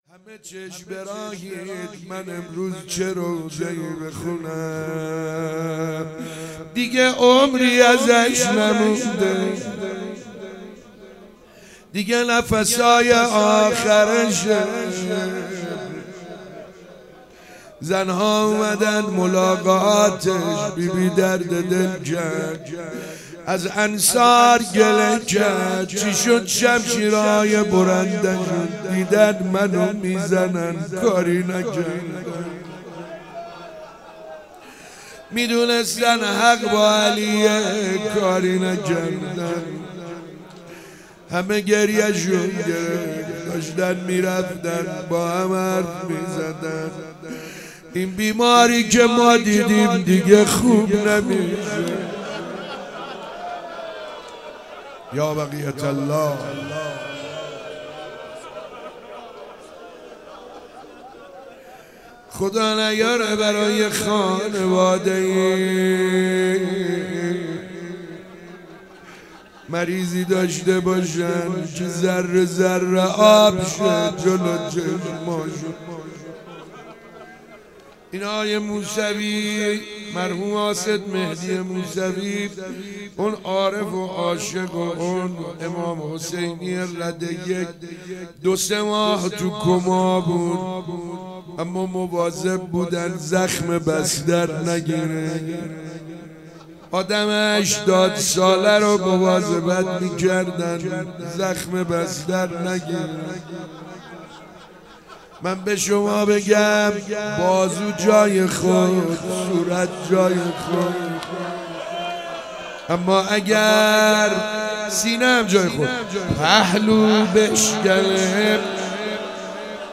تکیه | روضه حضرت زهرا سلام ا... علیها